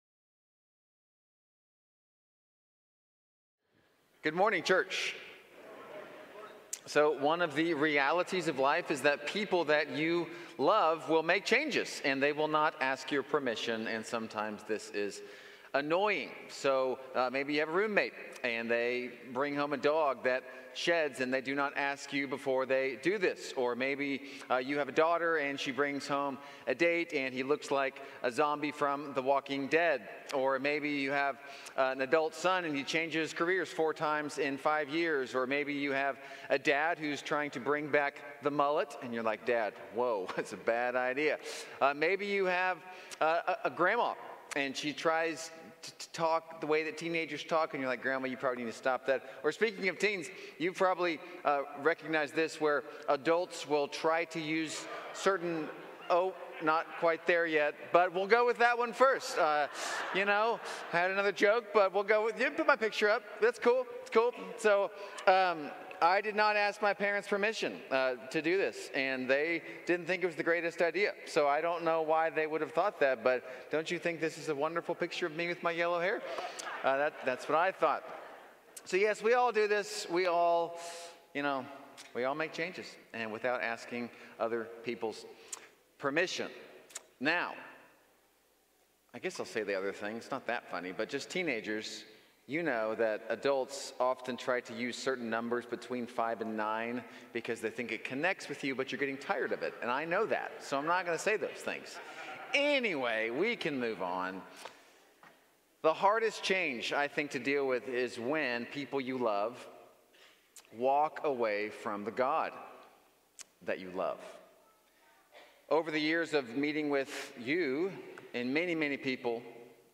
Series: Believe in Me, Sunday Morning